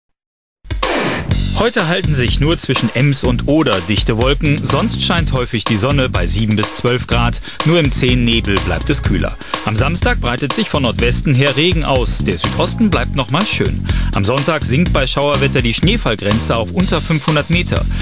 - exercices de compréhension auditive à partir de bulletins météos. (fiche).